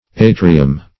Atrium \A"tri*um\, n.; pl. Atria. [L., the fore court of a